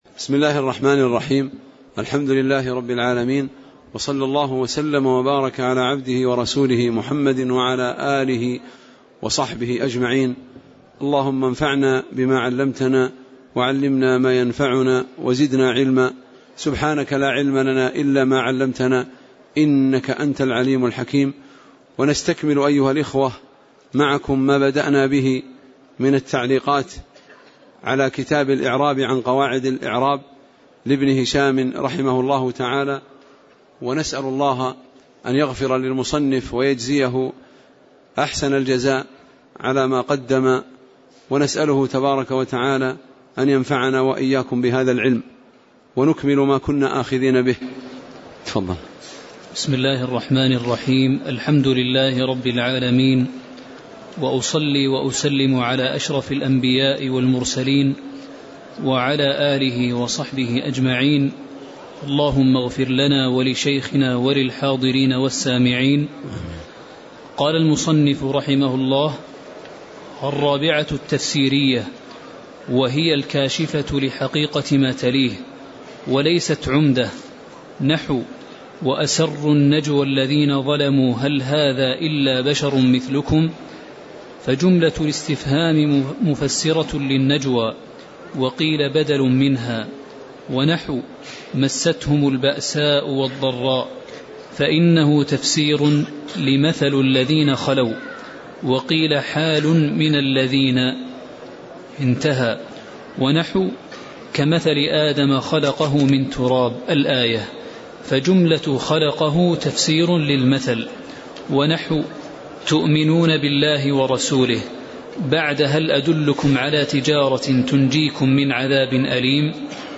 تاريخ النشر ٨ شوال ١٤٣٨ هـ المكان: المسجد النبوي الشيخ